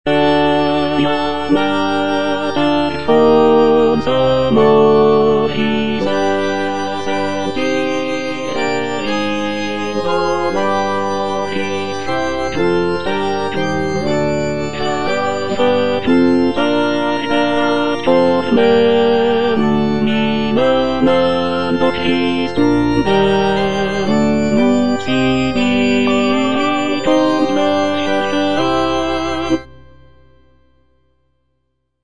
G.P. DA PALESTRINA - STABAT MATER Eja Mater, fons amoris (tenor II) (Emphasised voice and other voices) Ads stop: auto-stop
sacred choral work
Composed in the late 16th century, Palestrina's setting of the Stabat Mater is known for its emotional depth, intricate polyphonic textures, and expressive harmonies.